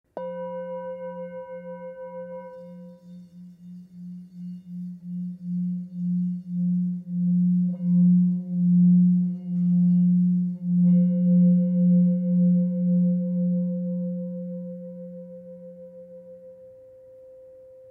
Tepaná tibetská mísa Chu o hmotnosti 1193 g, včetně paličky s kůží
tibetska_misa_v19.mp3